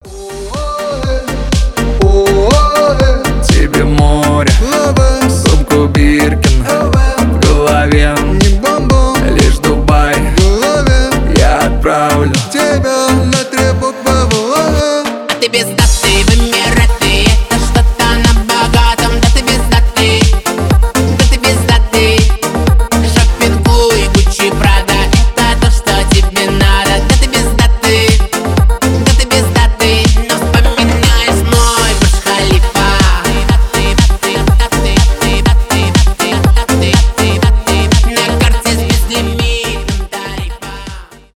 поп
танцевальные
веселые